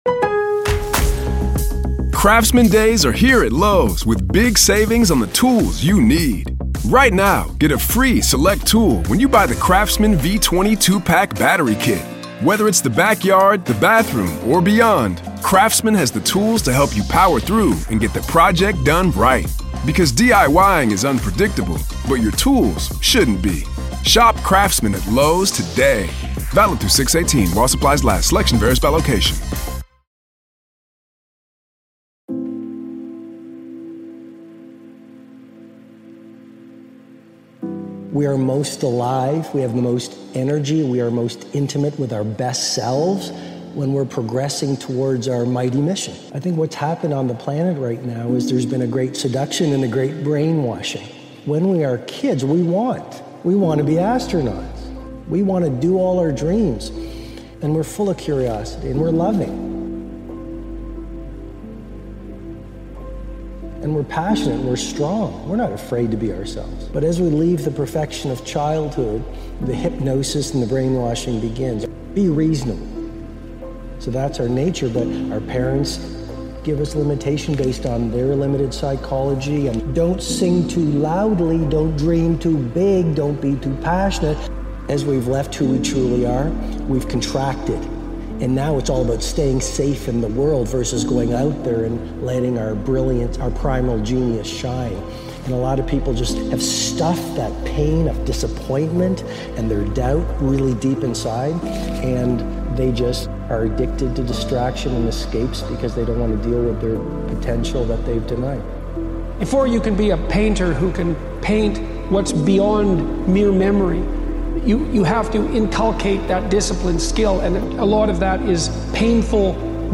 LIFE WILL HURT - POWERFUL Motivational Speech to Help You Rise Again – Motivational Speech – Podcast